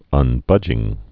(ŭn-bŭjĭng)